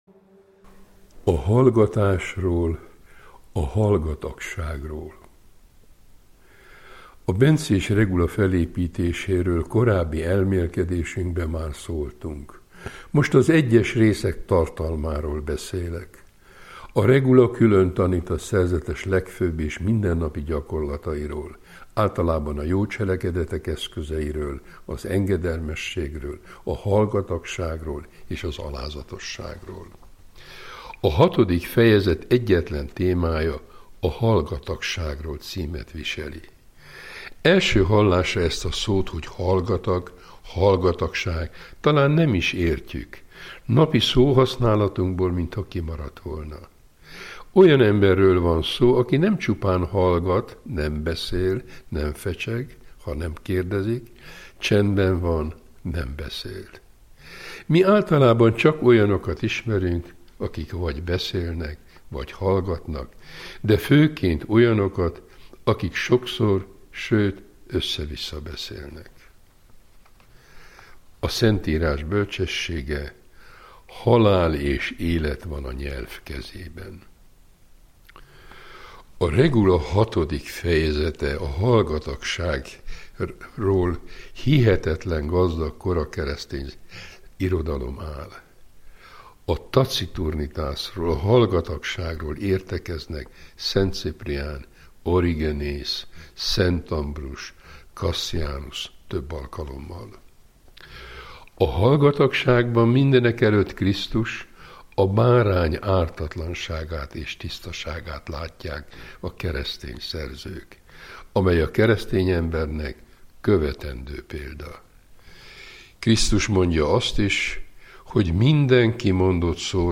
Szent Benedek öröksége Várszegi Asztrik emeritus pannonhalmi főapát tolmácsolásában